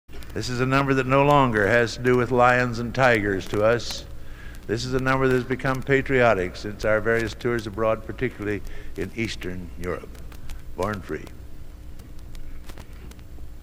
Location: West Lafayette, Indiana
Genre: | Type: Director intros, emceeing